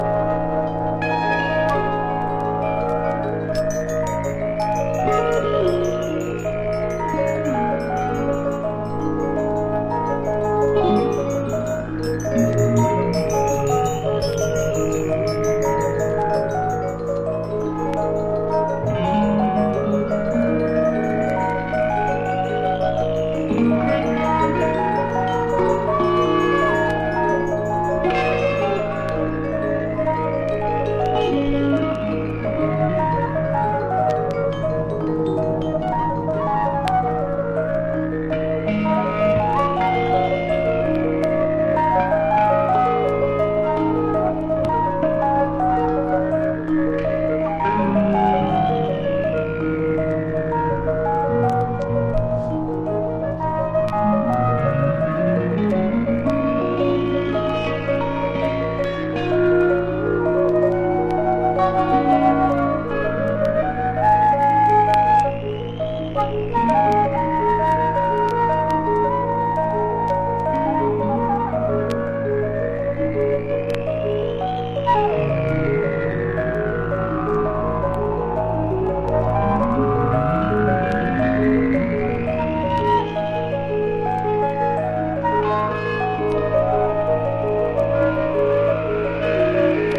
メディテーショナルでどこか厭世が香る形容しがたいサウンドの集合体。